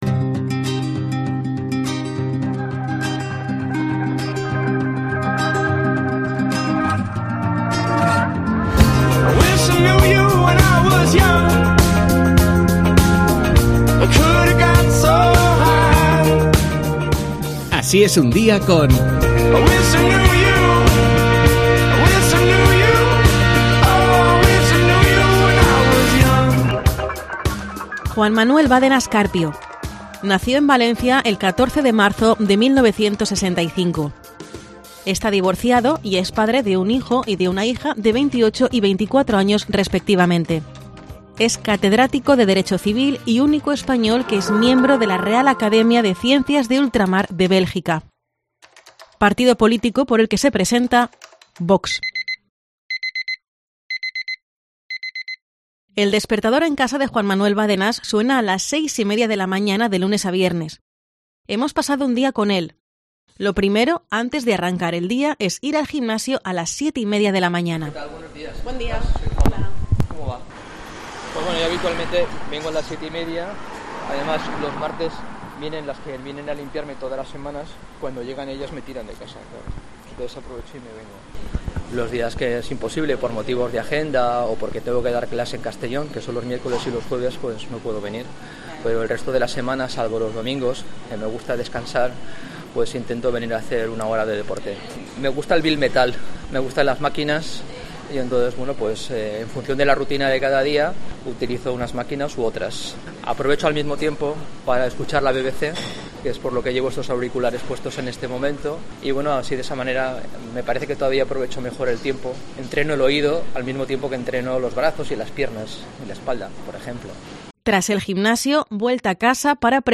Ya puedes escuchar, en formato podcast, cómo son 24 horas en la vida del candidato de VOX a la alcaldía de València. Los micrófonos de COPE València han recogido los sonidos de cada momento.